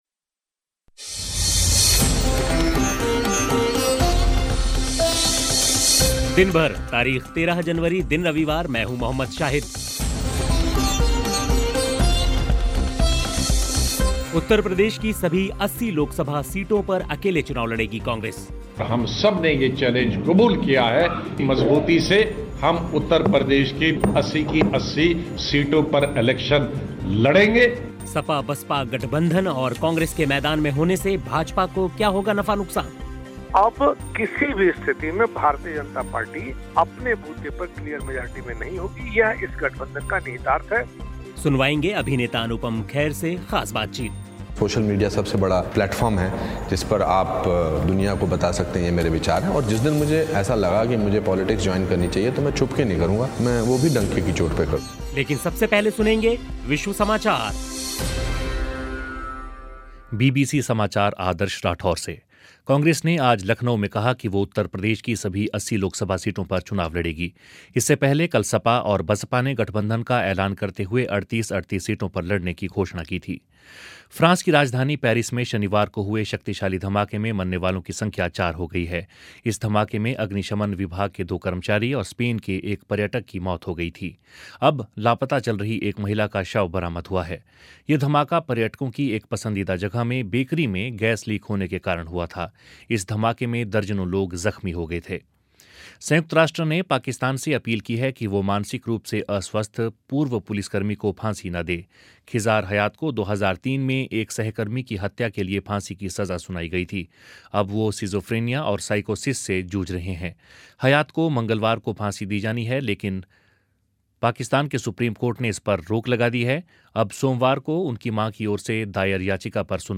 अभिनेता अनुपम खेर से ख़ास बातचीत साथ ही खेल की ख़बरें और विश्व समाचार भी होंगे.